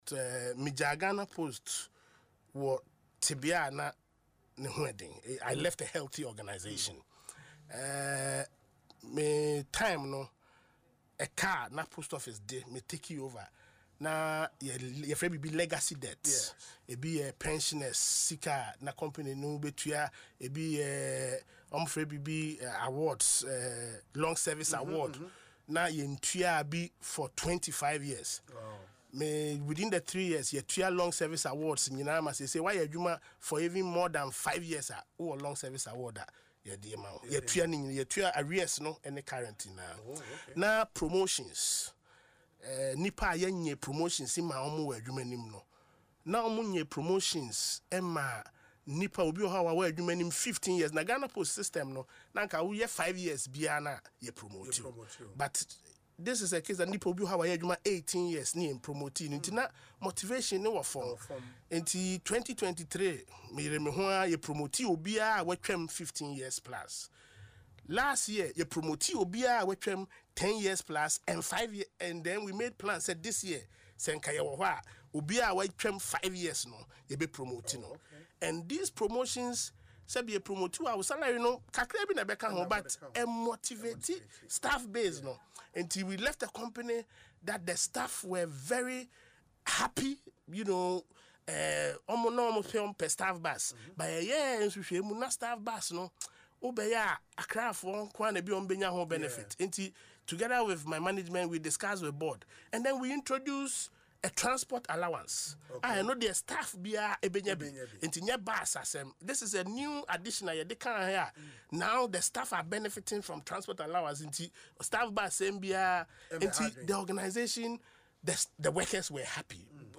In an interview on Asempa FM’s Ekosii Sen show, Obour reflected on the progress the company made under his leadership